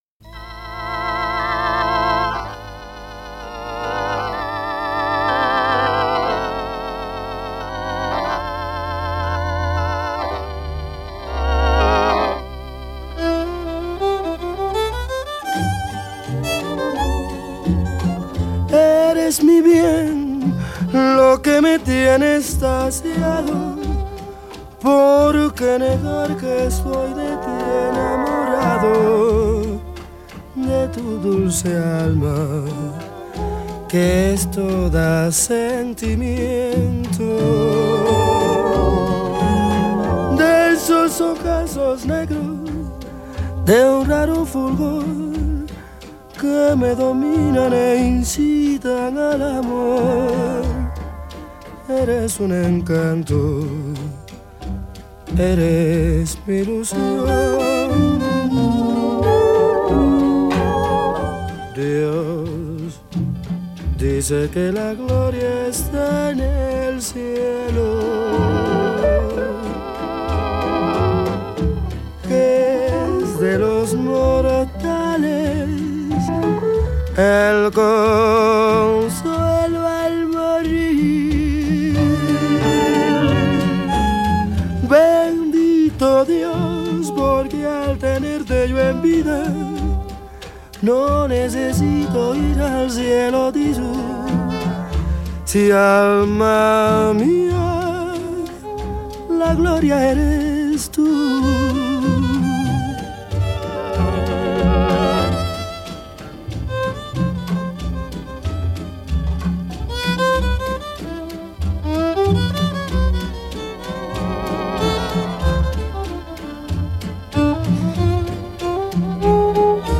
キューバ歌謡フィーリンの名盤復活！
＊以前のプレスよりも音に奥行きが出ていること、ヴォーカルが前に出て聞こえること、
そして、音の輪郭もハッキリしているんじゃないかと、その辺を是非お楽しみ下さい！
本ＣＤは50年代半ばにメキシコで録音された彼の最初のアルバムを中心に、同時期の貴重なライヴ音源も追加した内容。